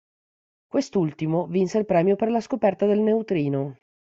sco‧pèr‧ta
/skoˈpɛr.ta/